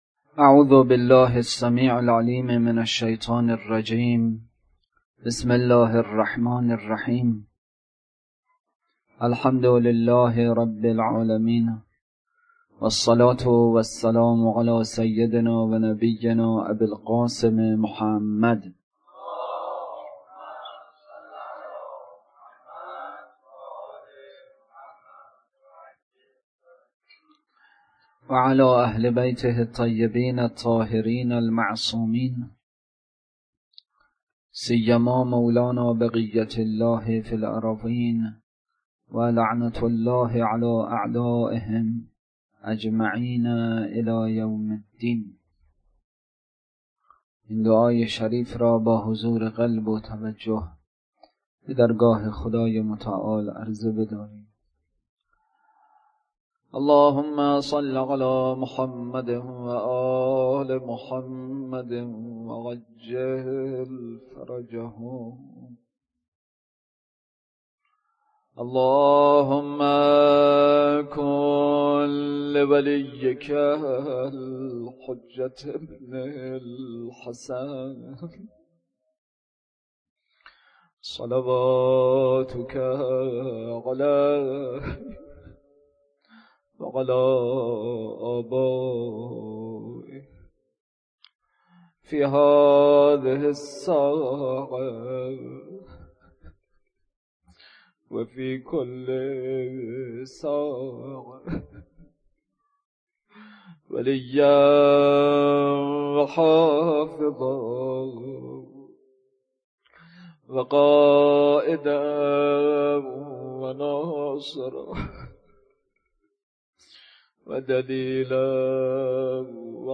سخنرانی
احکام: وظیفه‌ی اشخاص در رابطه با چیزی که از عقد باطل به دست آورده‌اند (مقبوض به عقد فاسد) بحث اصلی: راه‌های افزایش حیا (راه دوم و سوم: کنترل فکر و زبان) روضه: عطش، حضرت علی اصغر(ع)